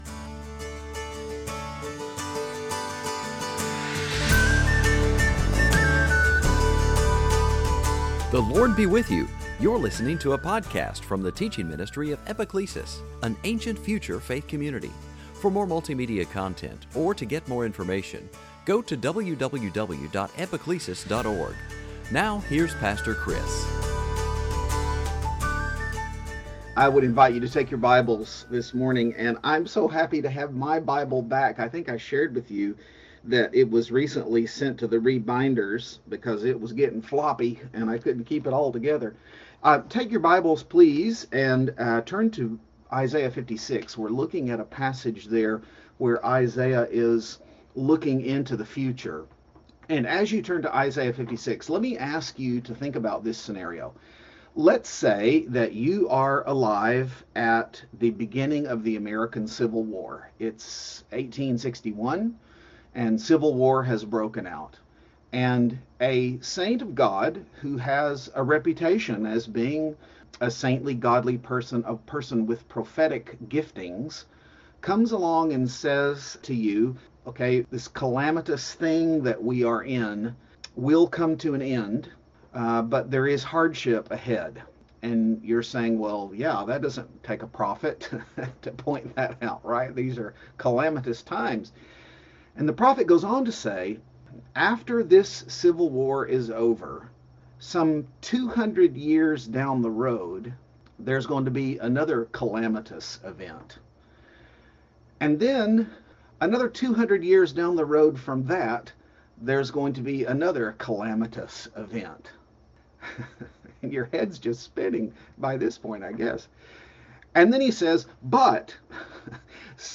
Series: Sunday Teaching